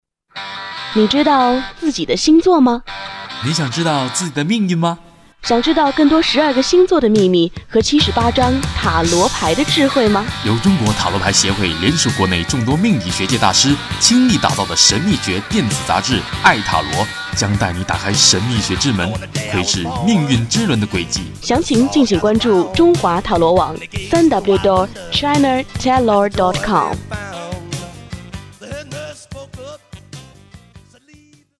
这是多年前7Radio工作室为我们中塔专门录制的电台宣传，我一直把它做为我的手机铃声，分享给咱们的会员下载。
哇。超电台的。
比较像广告也。。。